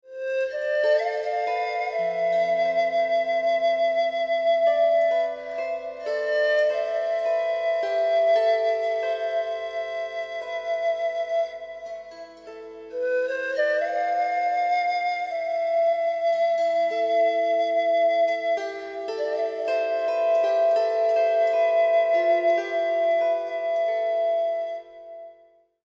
（インスト＝インストゥルメンタル：唄のない楽器演奏のみの曲）
唄なしのインストなので、純粋に楽曲の魅力を堪能していただけます。